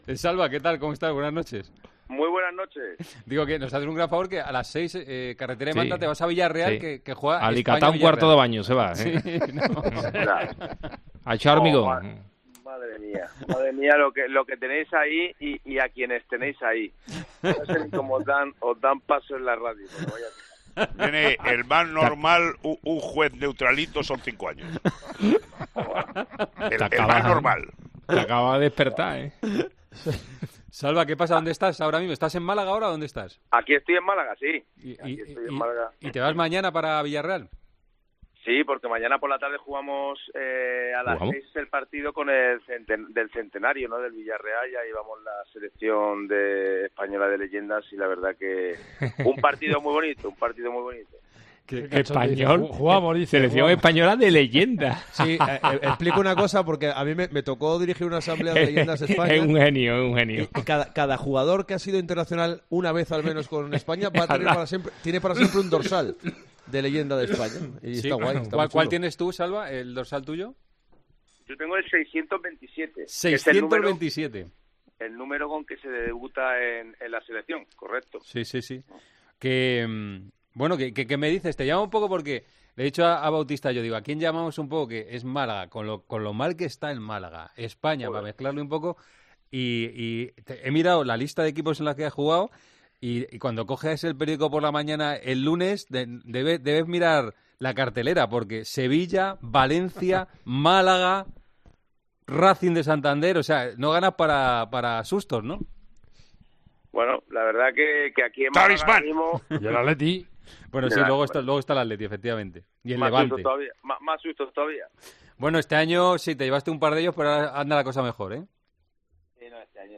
AUDIO: El exdelantero español analizó, en El Partidazo de COPE, a la nueva España de Luis de la Fuente.